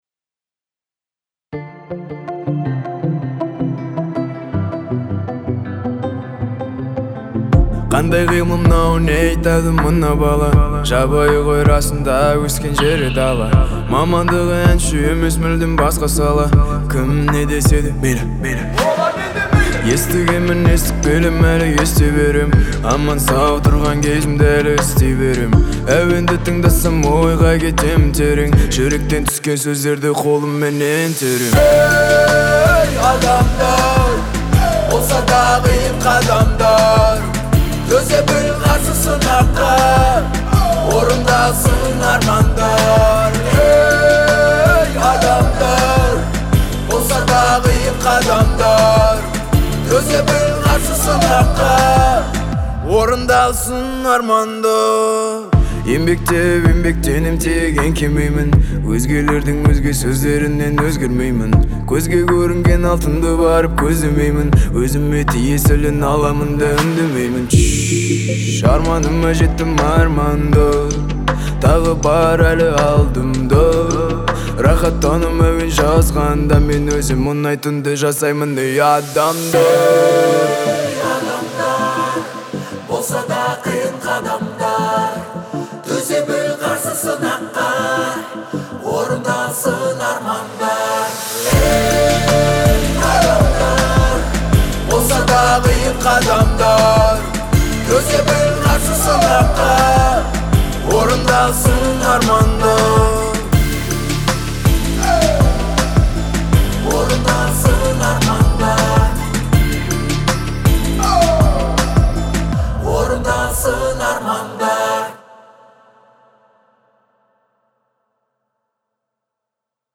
это вдохновляющая песня в жанре поп